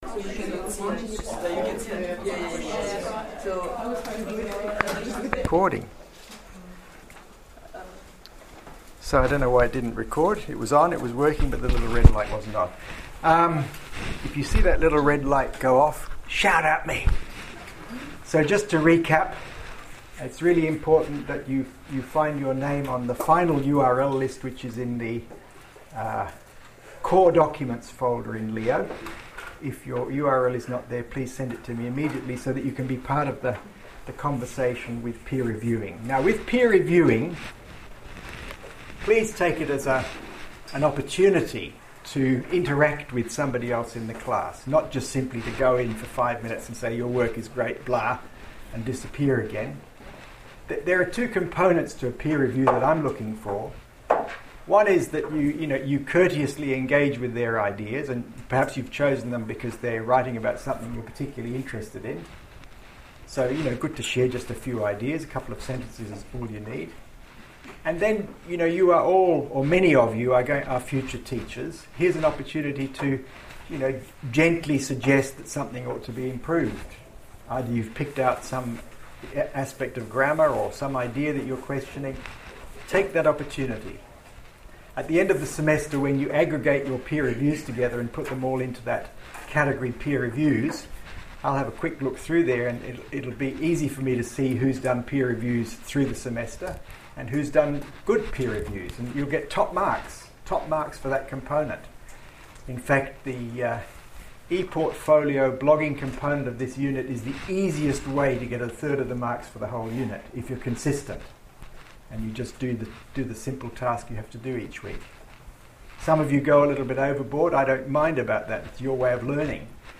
lecture-week-4-whiteley-blake.mp3